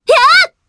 Frey-Vox_Attack2_jp.wav